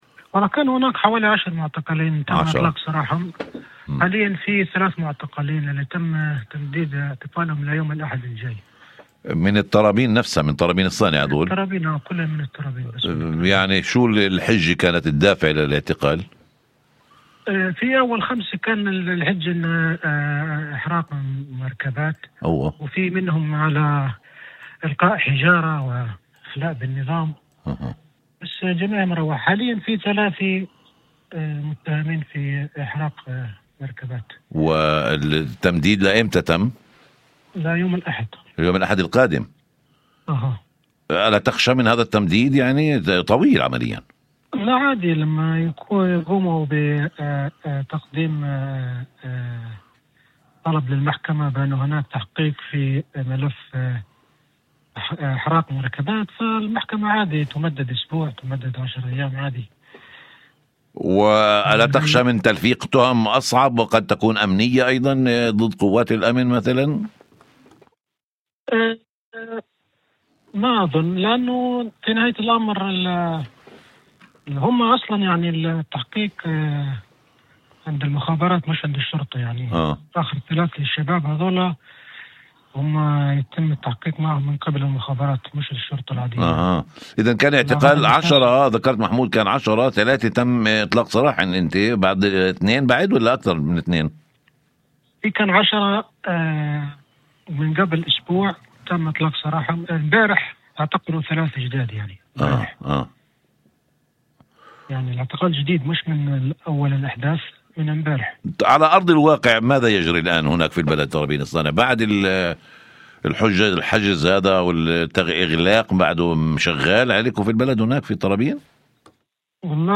في مداخلة ضمن برنامج "أول خبر" على إذاعة الشمس